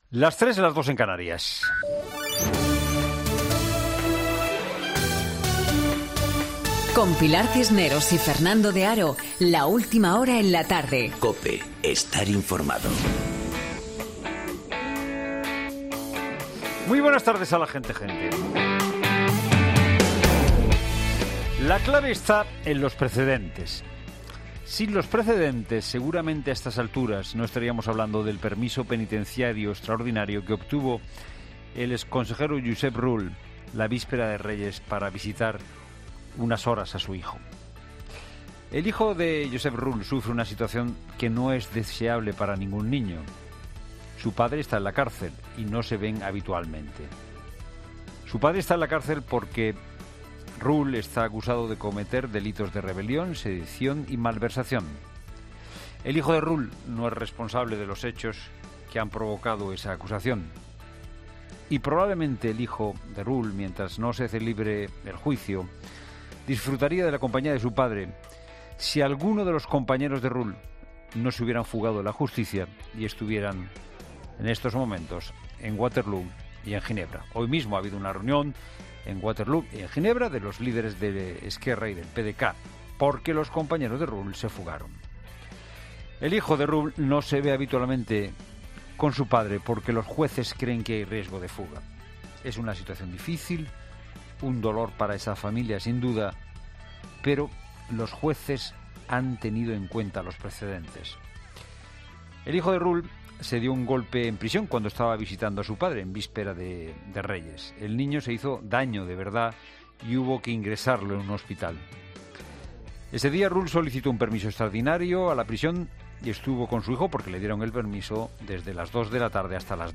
Monólogo de Fernando de Haro